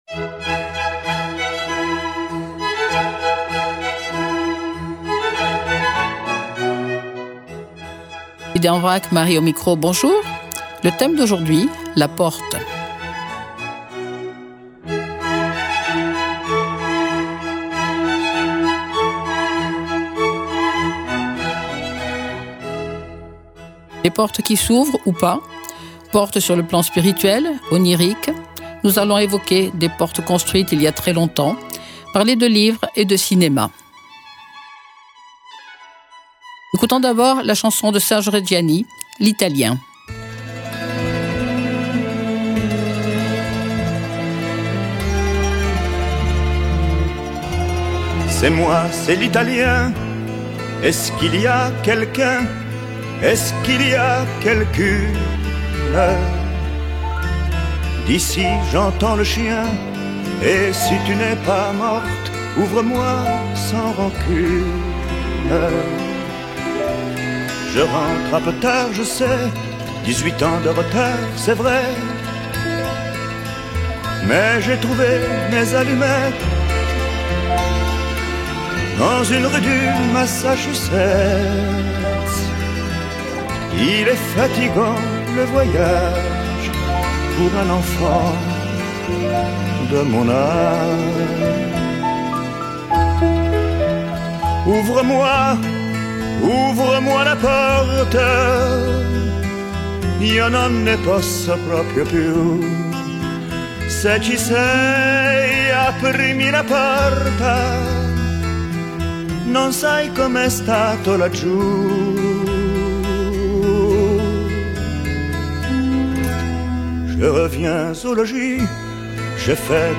Les portes qui s’ouvrent ou pas, portes sur le plan spirituel, onirique, nous évoquerons des portes construites il y a très longtemps, parler de livres et de cinéma, le tout accompagné de chansons de tous horizons...